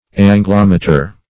Search Result for " angulometer" : The Collaborative International Dictionary of English v.0.48: Angulometer \An"gu*lom"e*ter\, n. [L. angulus angle + -meter.] An instrument for measuring external angles.